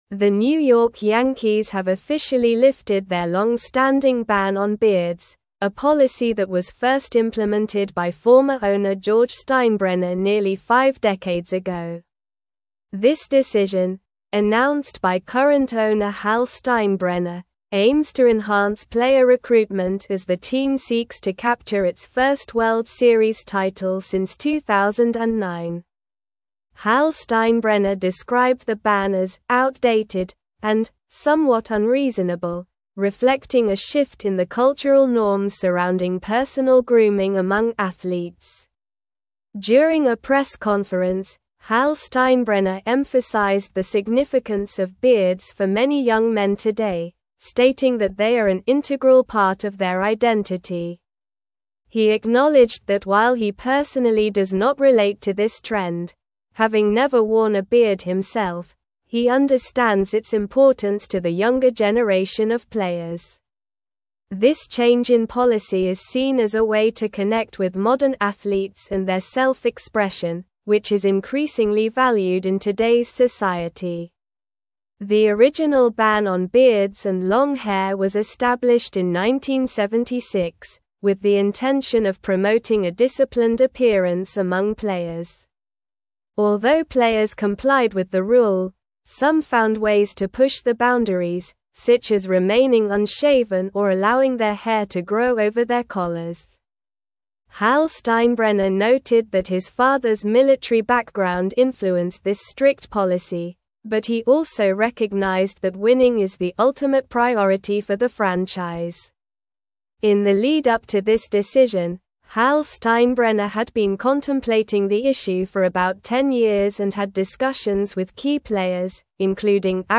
Slow
English-Newsroom-Advanced-SLOW-Reading-Yankees-Lift-Longstanding-Beard-Ban-for-Players.mp3